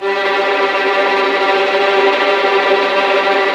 Index of /90_sSampleCDs/Roland LCDP08 Symphony Orchestra/STR_Vls Tremolo/STR_Vls Trem wh%